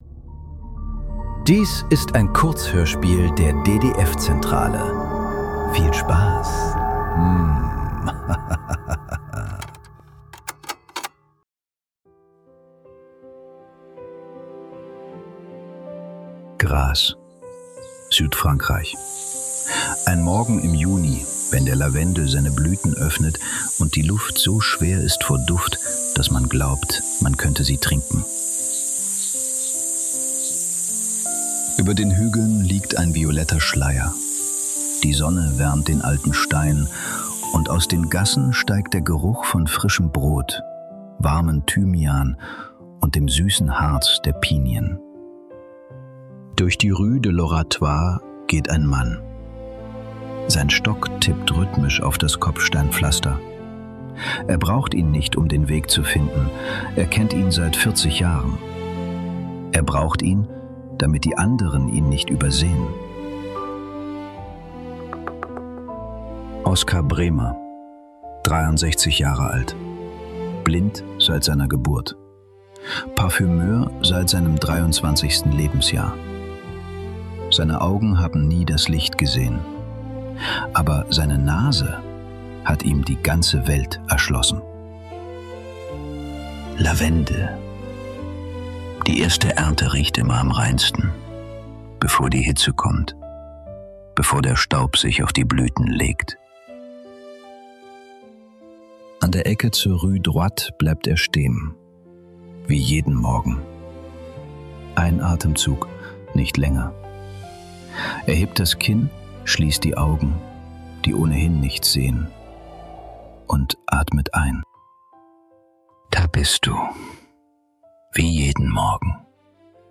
Der letzte Duft ~ Nachklang. Kurzhörspiele.